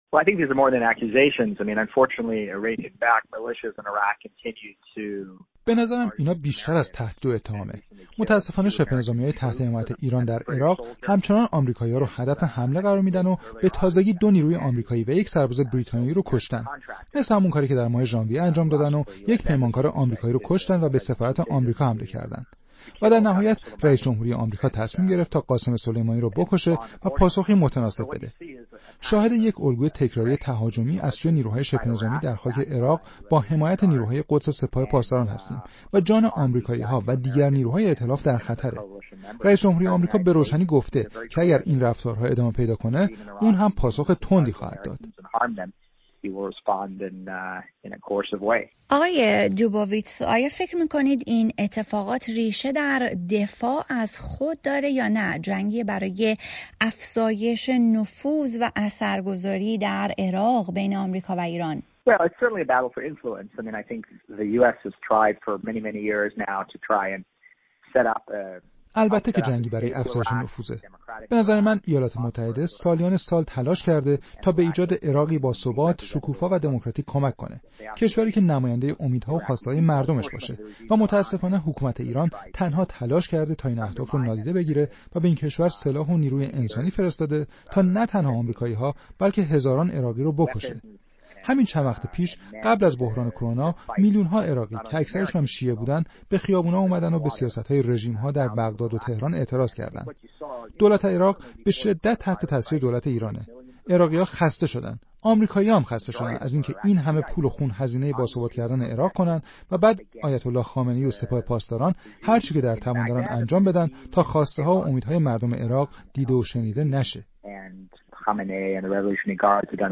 حتی شیوع ویروس کرونا و تعطیل شدن فعالیت‌ها در بسیاری از کشورها، منازعه لفظی میان ایران و ایالات متحده را در خاک عراق خاتمه نداده و دو کشور همچنان یکدیگر را به آتش‌افروزی و حمله به منافع هم متهم می‌کنند. در گفت‌وگویی با مارک دوبوویتز مدیر بنیاد دفاع از دموکراسی‌ها، نظر او را در این مورد جویا شده‌ایم.